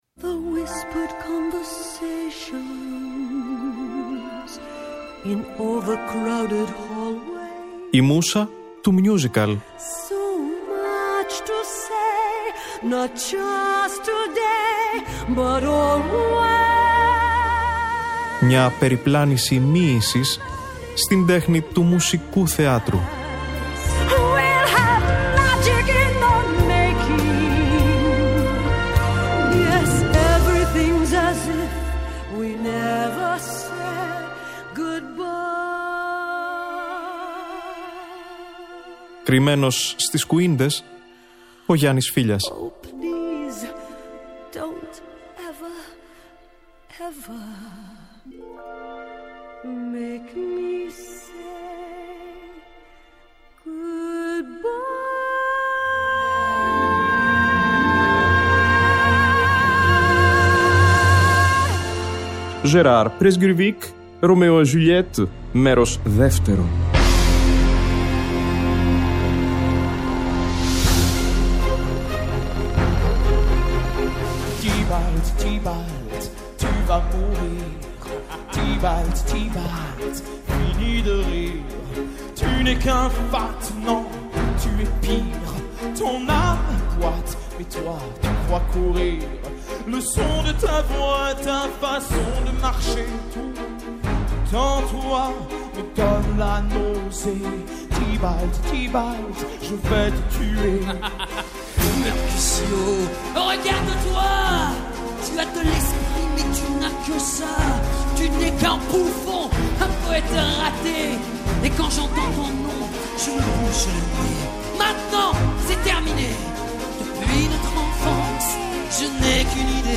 ζωντανή ηχογράφηση της πρεμιέρας
Soundtracks